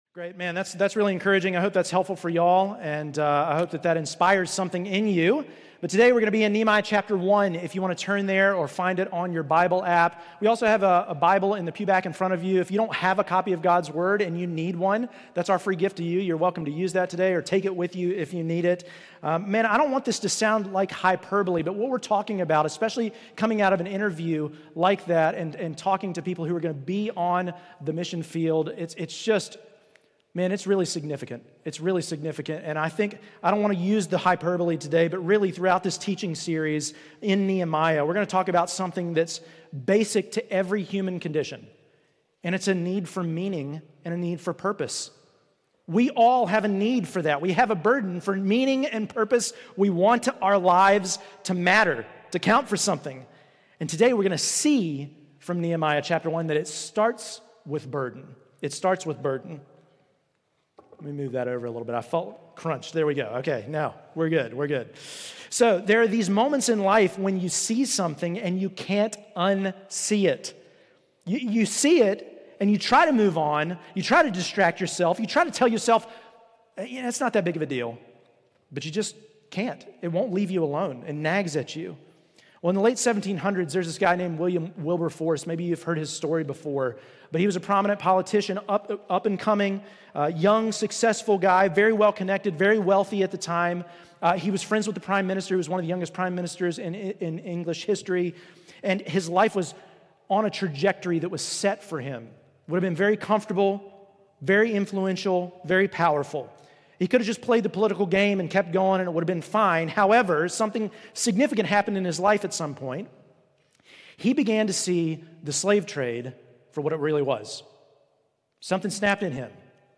Redemption Church Sermons It Starts With a Burden Mar 01 2026 | 00:48:14 1x 00:00 / 00:48:14 Subscribe Share Apple Podcasts Spotify Overcast RSS Feed Share Link Embed